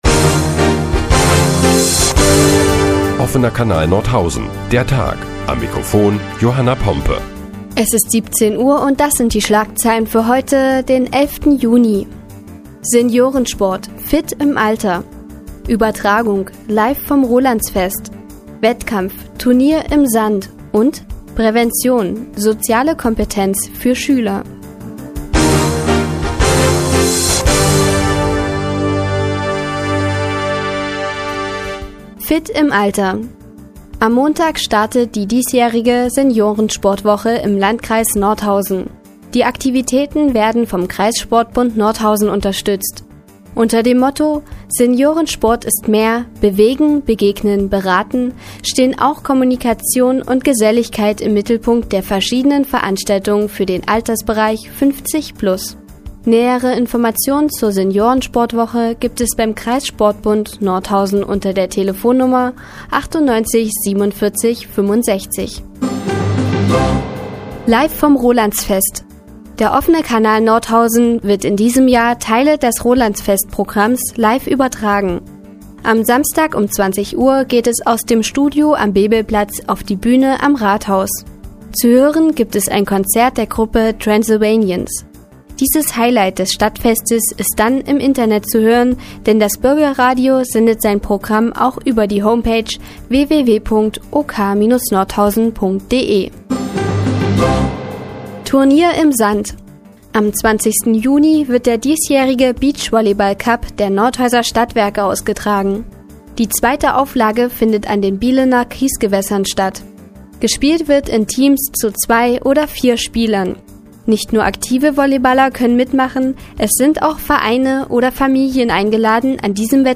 Die tägliche Nachrichtensendung des OKN ist nun auch in der nnz zu hören. Heute geht es unter anderem um sportliche Senioren und soziale Schüler.